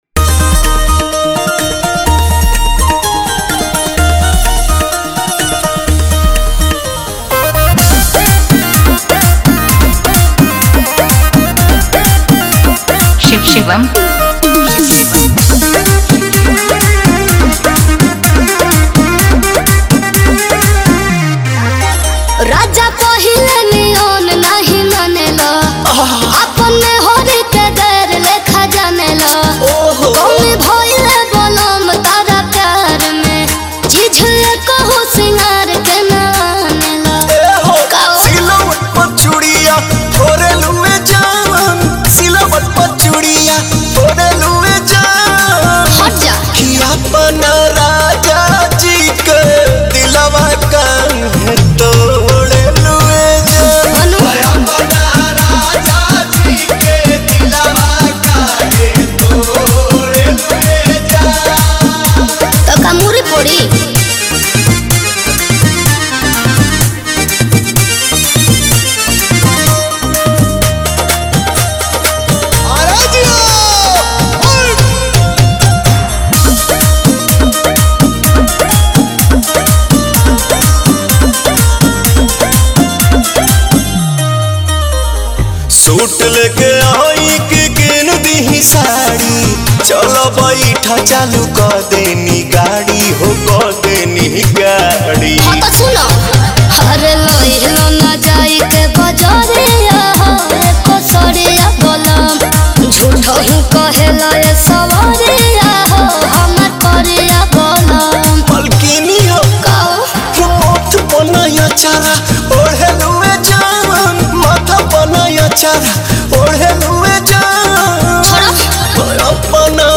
Bhojpuri Old Filters Dj Remix